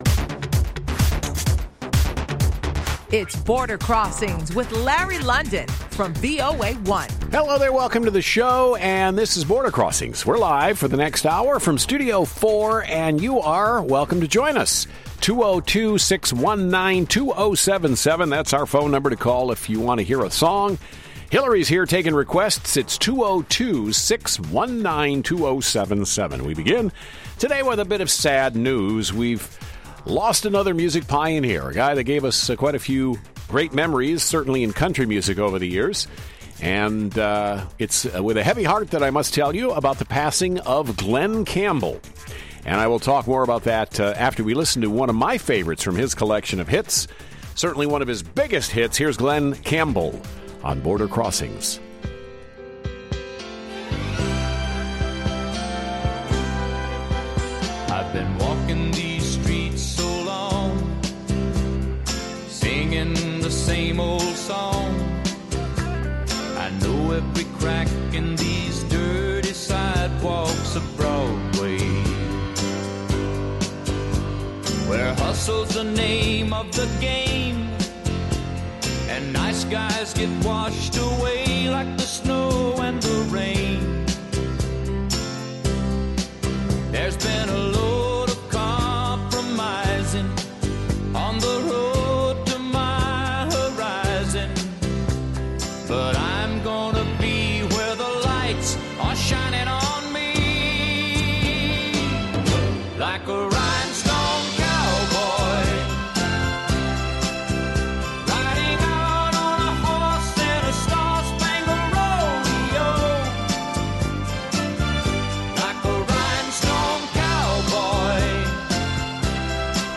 VOA’s live worldwide international music request show